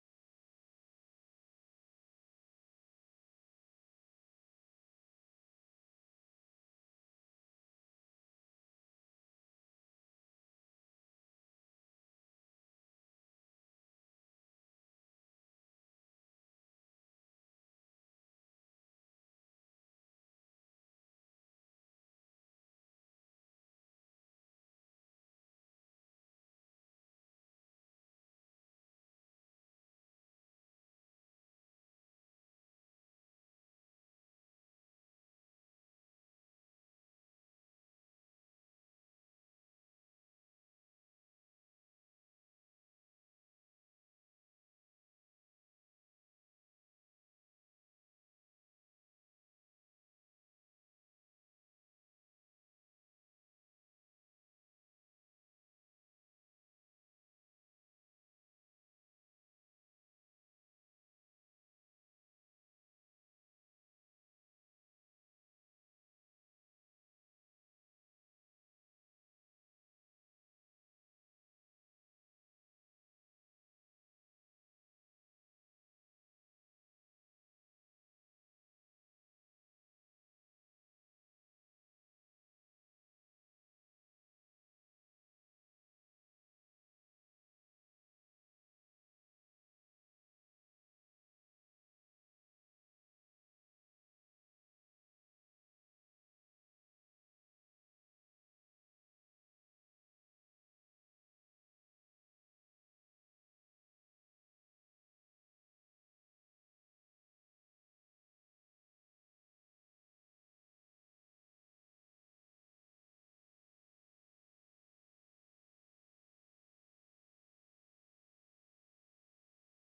Some of theses tapes where not of the best condition and are 25 years old I am missing Mark and Luke.